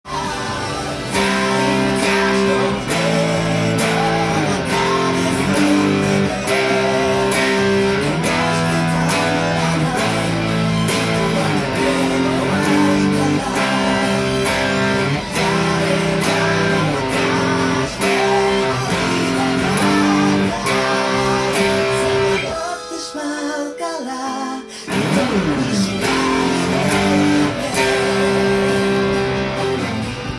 オリジナル　カンタンtab譜
音源にあわせて譜面通り弾いてみました
keyはCメジャーになります。
サビの部分をパワーコードでカンタンに弾けるように